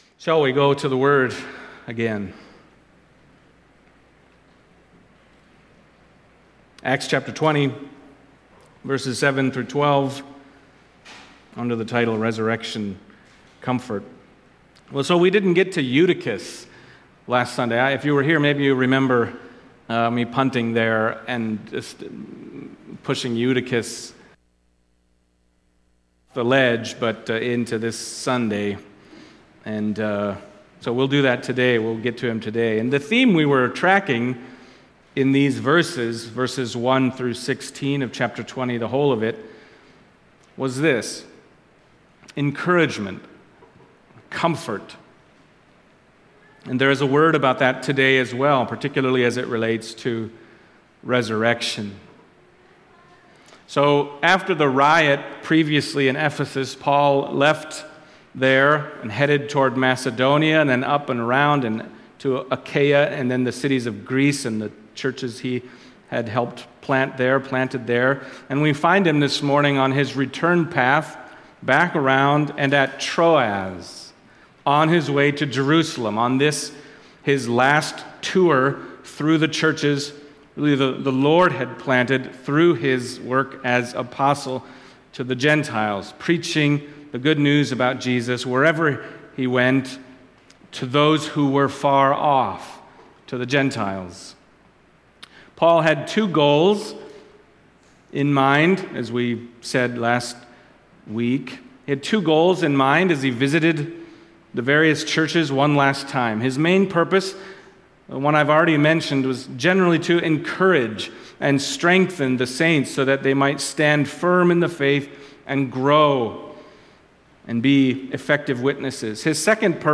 Acts Passage: Acts 20:7-12 Service Type: Sunday Morning Acts 20:7-12 « Paul Encourages the Churches Paul’s Emotional Farewell